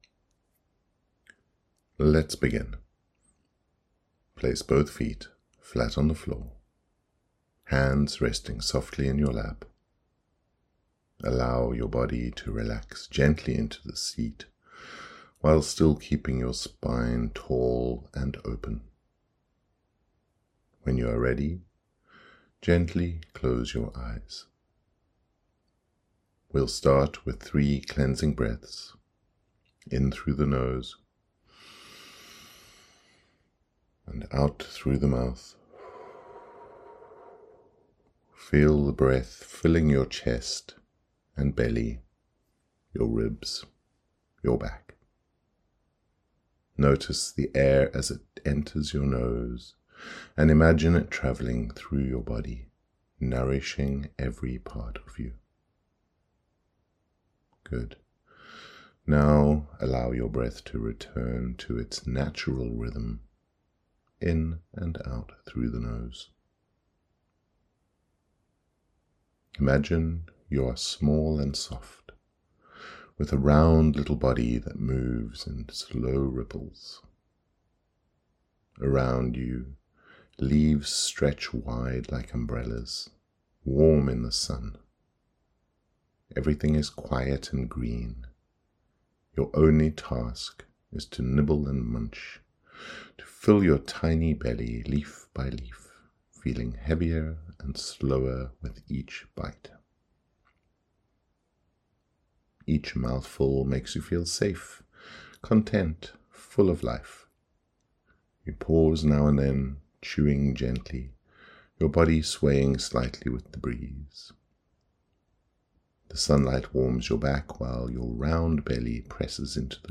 Slow and Steady Meditation
BL03-meditation.mp3